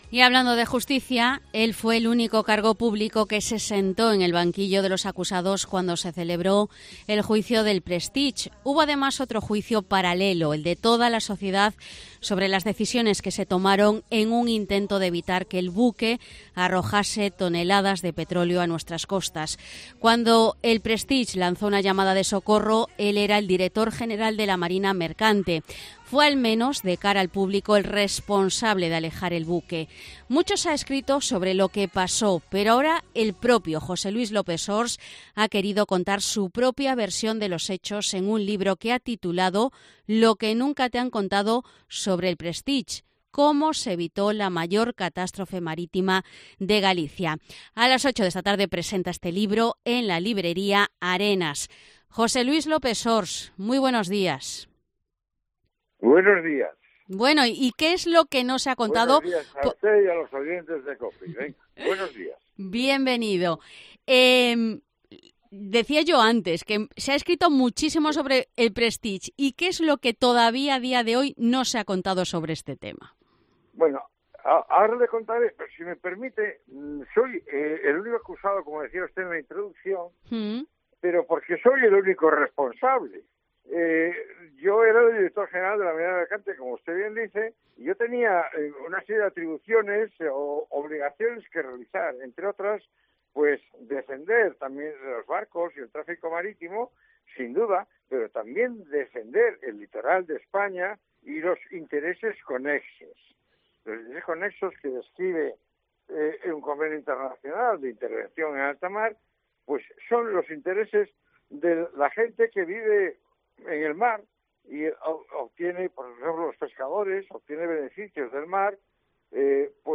Entrevista José Luis López-Sors